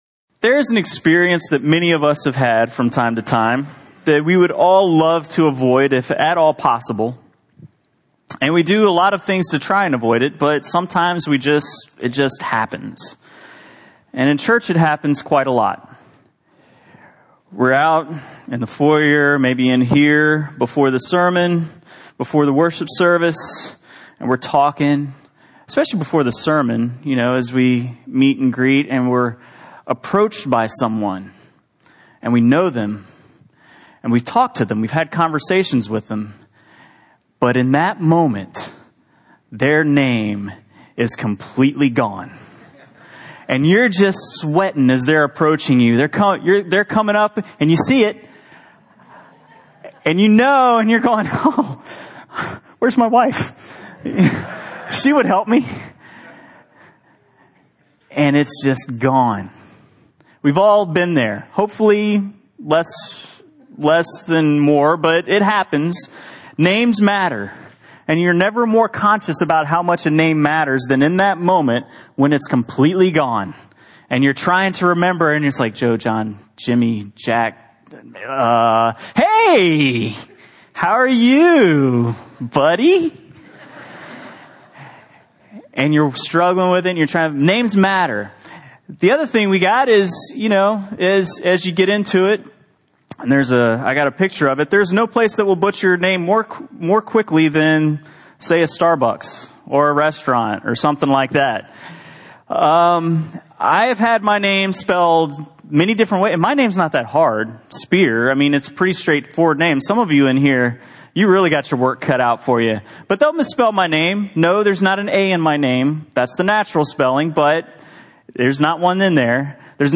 What's in a Name? Sermon Series Message 2